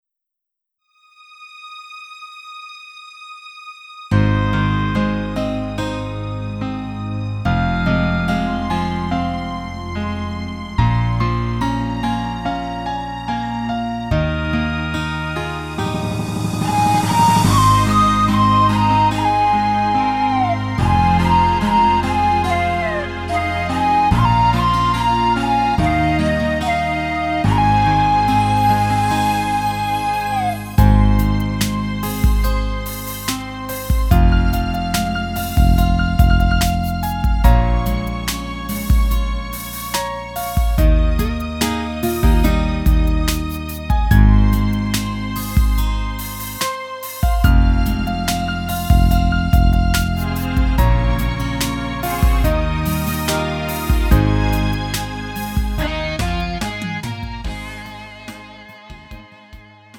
음정 원키 3:39
장르 구분 Lite MR
Lite MR은 저렴한 가격에 간단한 연습이나 취미용으로 활용할 수 있는 가벼운 반주입니다.